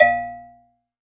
BCOWBELL.wav